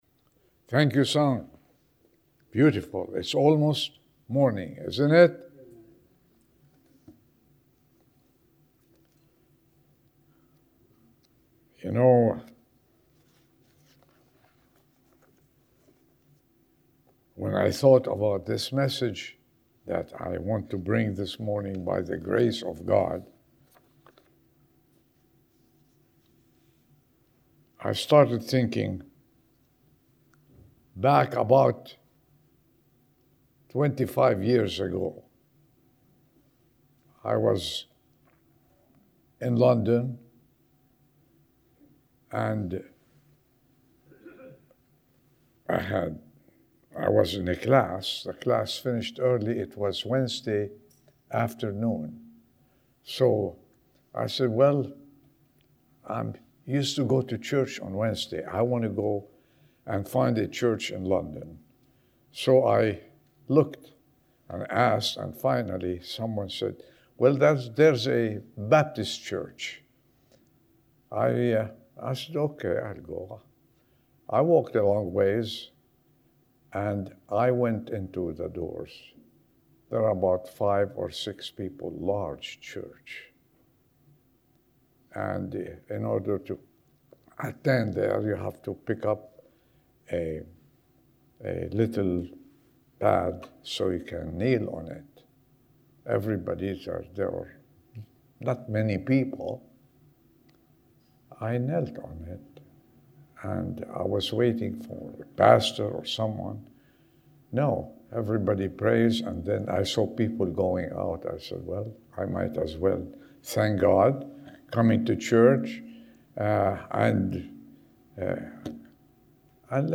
Sermon 02/15/2026 Why Do I Go To Church?